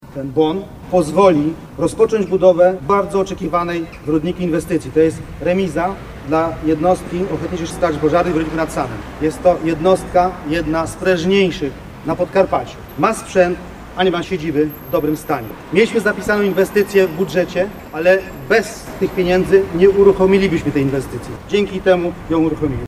Mówi burmistrz Rudnika Waldemar Grochowski